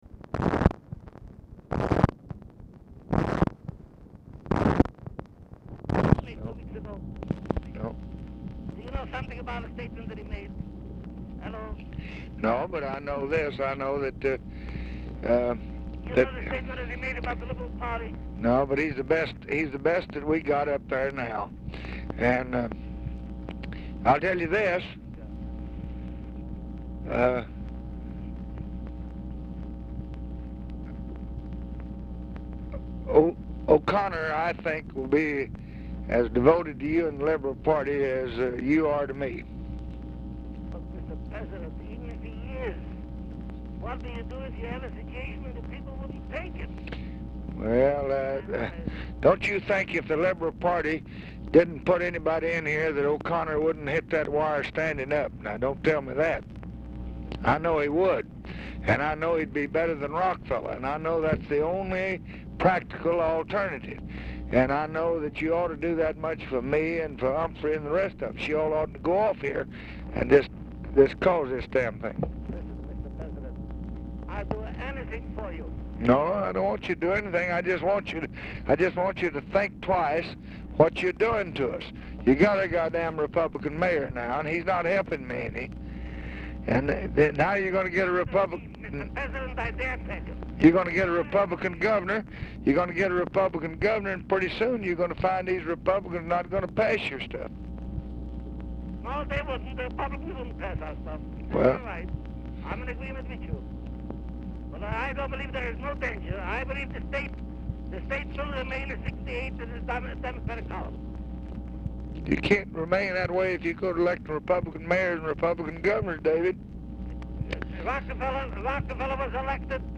Telephone conversation
DUBINSKY IS DIFFICULT TO HEAR; RECORDING IS BRIEFLY INTERRUPTED IN MIDDLE OF CALL
Format Dictation belt